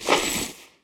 equip_chain2.ogg